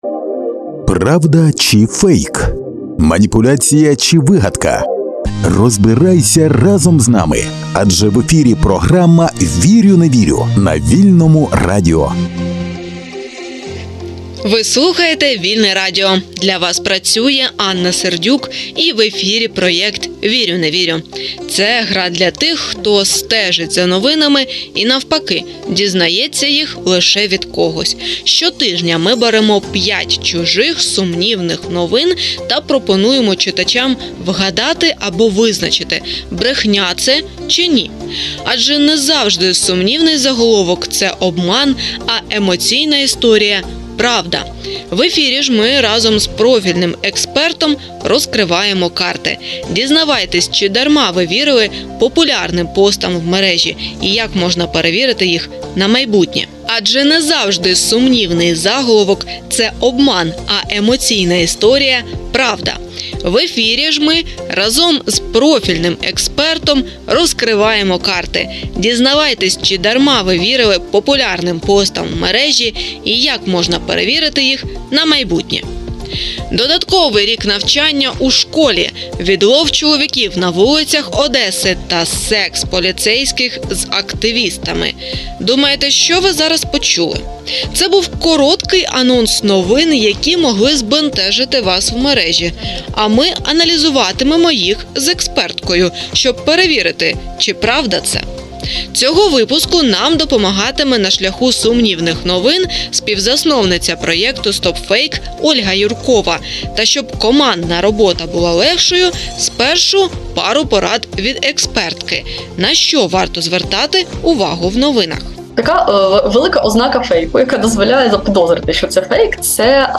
Ми прагнемо дати вам для цього всі інструменти, тому щотижня проводимо міні-гру, де пропонуємо вам проаналізувати 5 сумнівних новин. А потім розкриваємо карти з експертом та пояснюємо, на що варто звертати увагу, гортаючи стрічку новини.